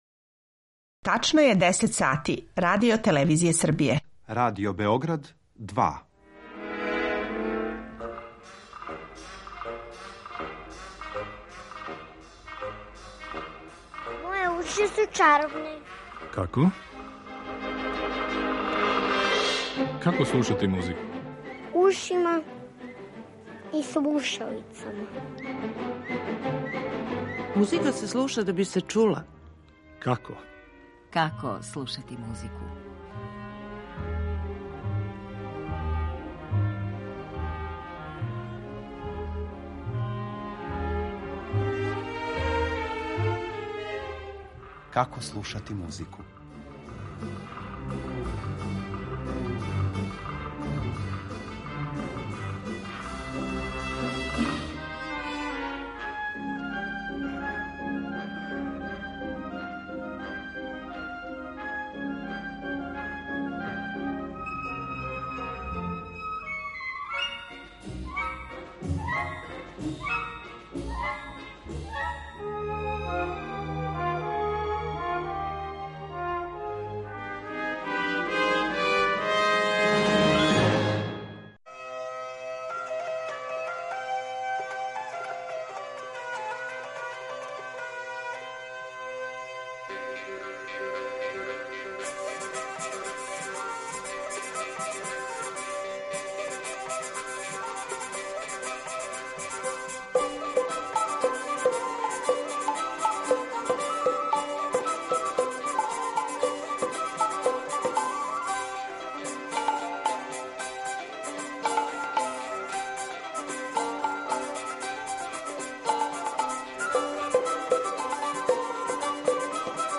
У емисији ћете имати прилику и да чујете одломке мање познатих, као и чувених балета који су обележили историју овог жанра у музици.